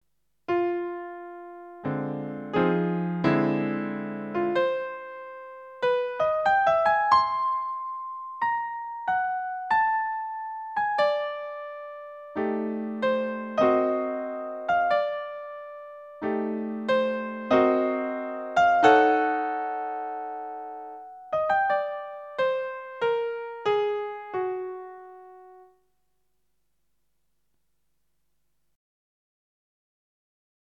039 - Lecture du texte en entier